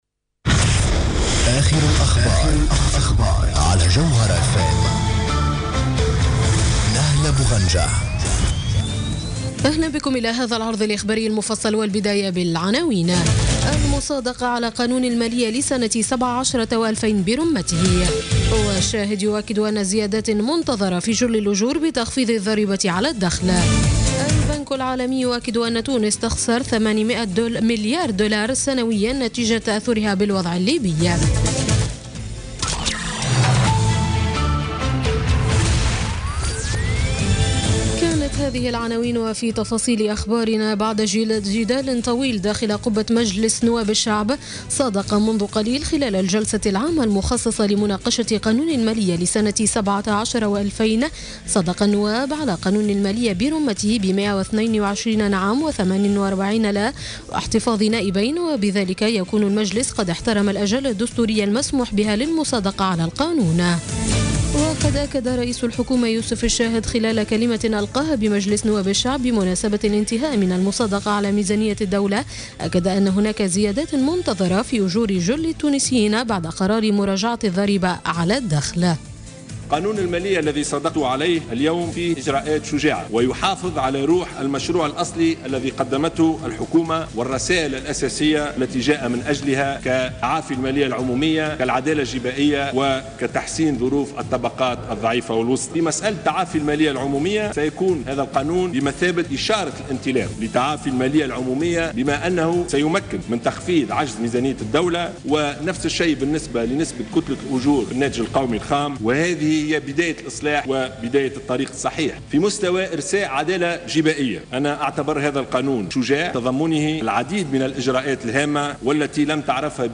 نشرة أخبار السابعة مساء ليوم السبت 10 ديسمبر 2016